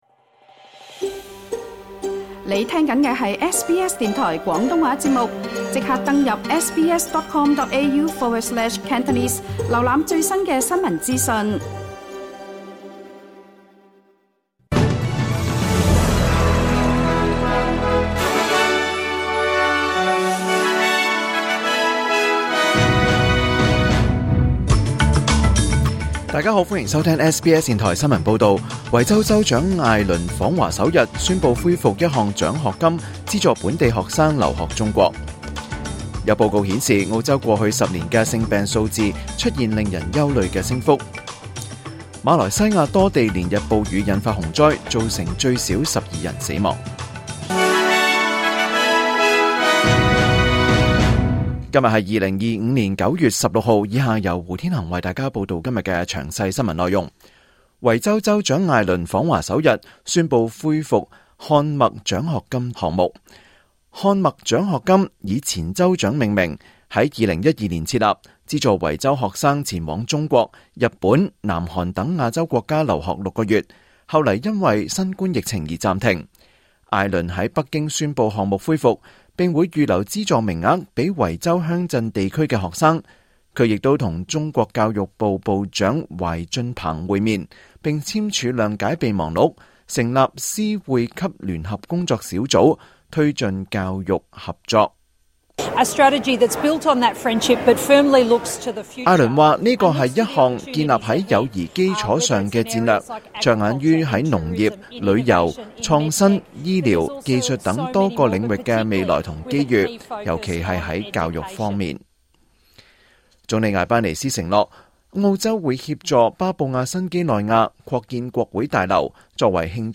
2025 年 9 月 16 日 SBS 廣東話節目詳盡早晨新聞報道。